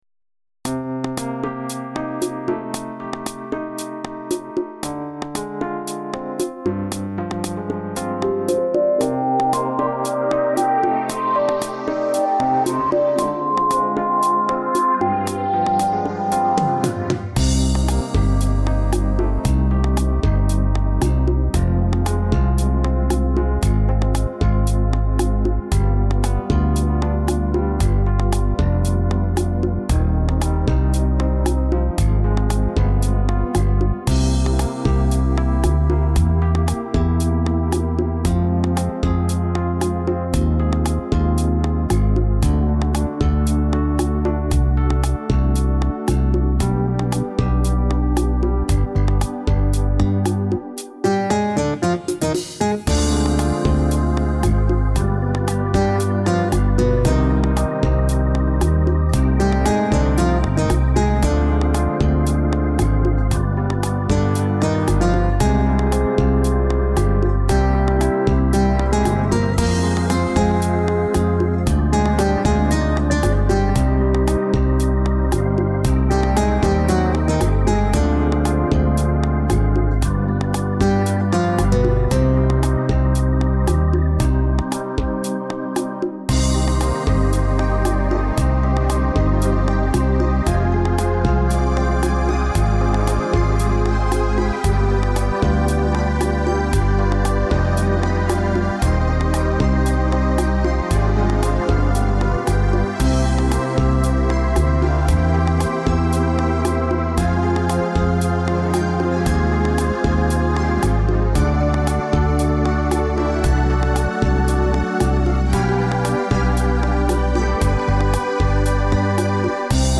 Пойте караоке
минусовка версия 31434